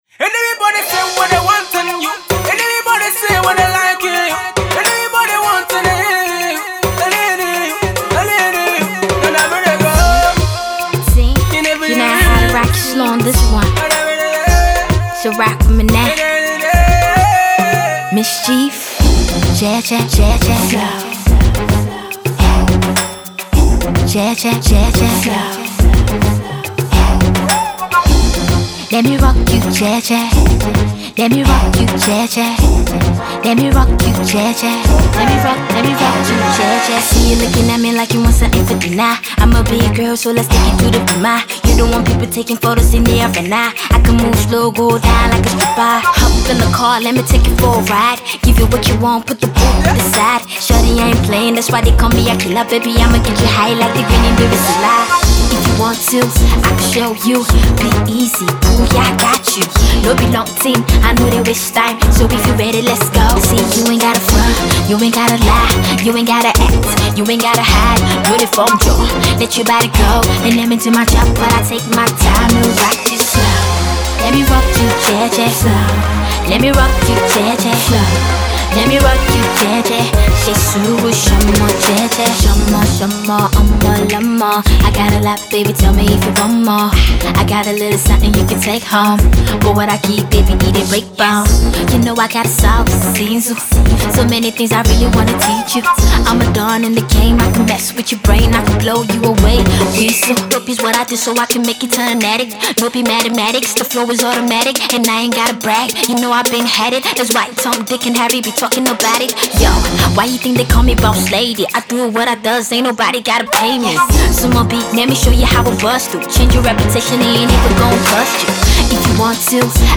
bumping production
With an exciting vibe and on-point lyrics and flow